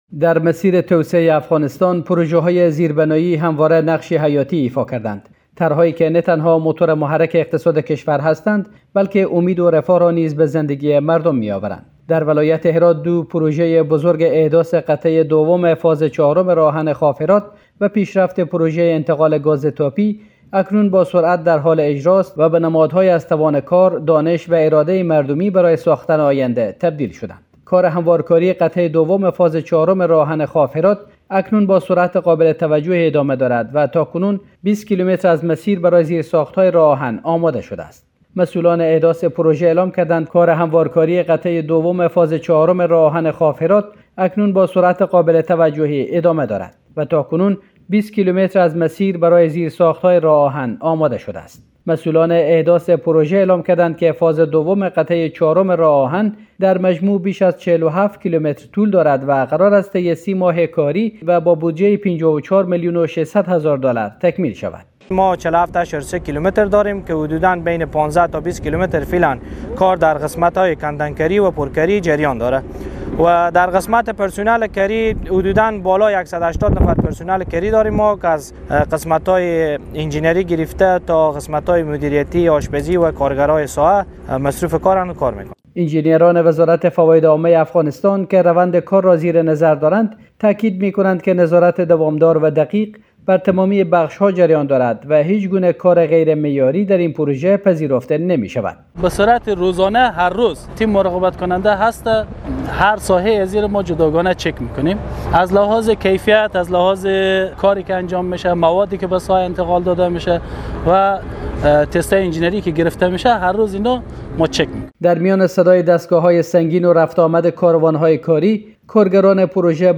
در میان صدای دستگاه‌های سنگین و رفت‌وآمد کاروان‌های کاری، کارگران پروژه با خرسندی از ایجاد فرصت‌های شغلی سخن می‌گویند.